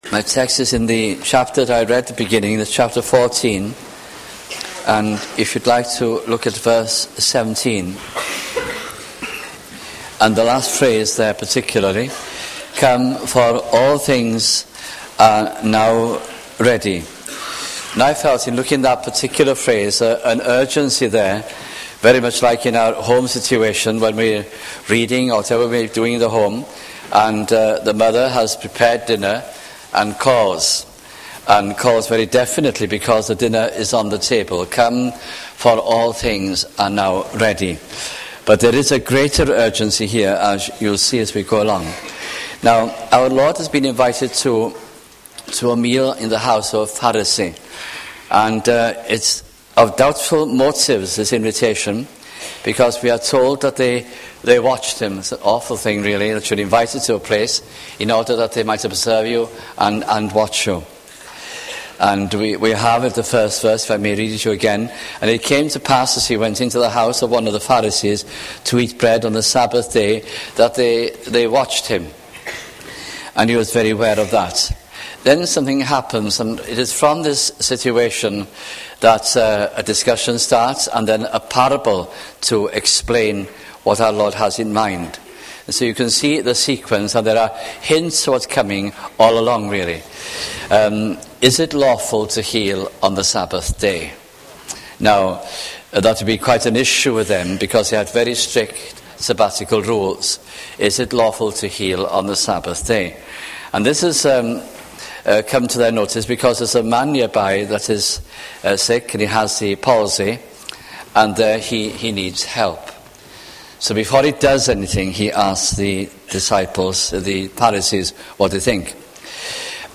» Luke Gospel Sermons